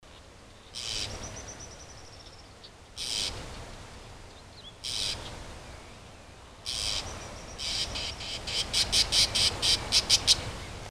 Calhandra-de-três-rabos (Mimus triurus)
Nome em Inglês: White-banded Mockingbird
Fase da vida: Adulto
Condição: Selvagem
Certeza: Observado, Gravado Vocal